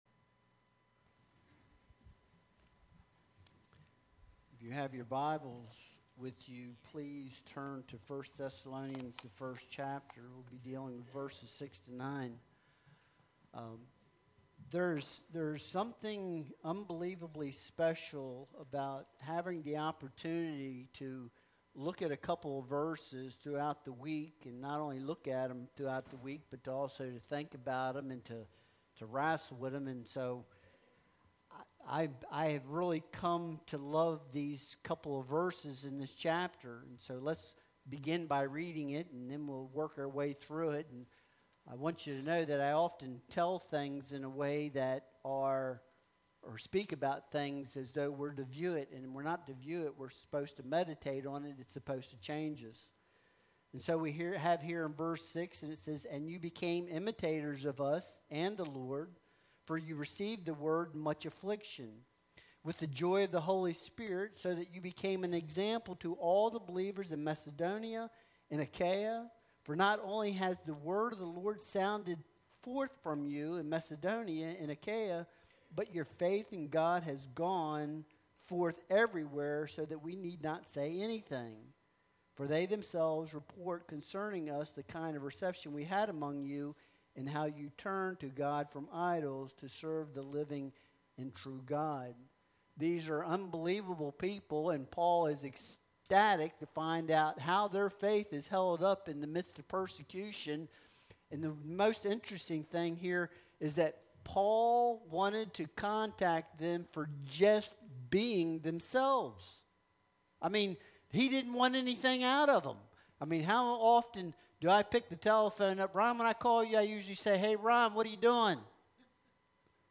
1 Thessalonians 1.6-9 Service Type: Sunday Worship Service Download Files Bulletin « Anticipating the Parousia